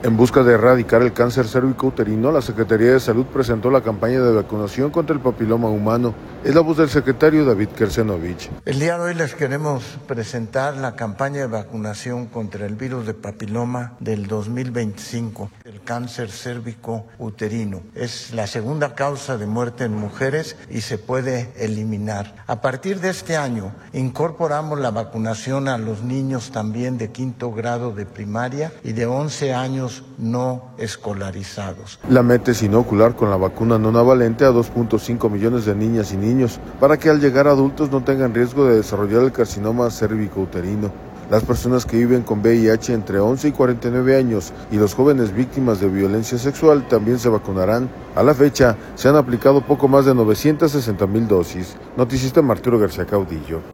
En busca de erradicar el cáncer cervicouterino, la Secretaría de Salud presentó la campaña de vacunación contra el papiloma humano. Es la voz del secretario David Kershenovich.